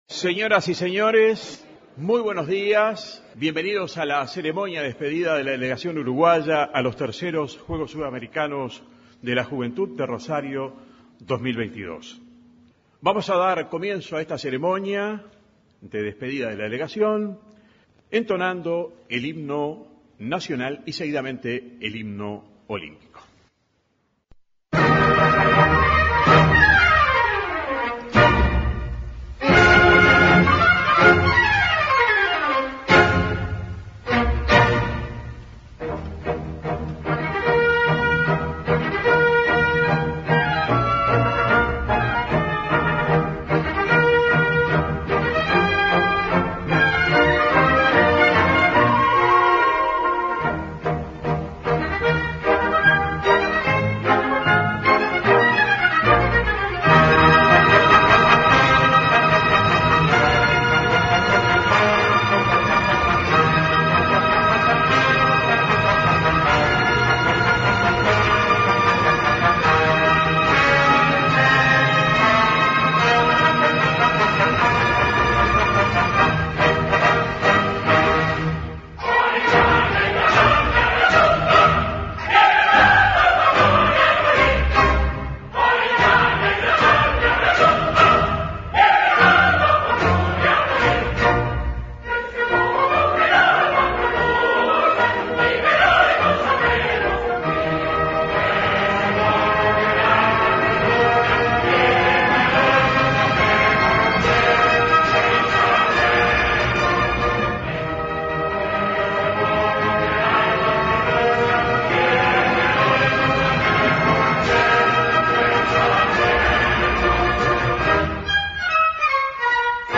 En el auditorio de la Torre Ejecutiva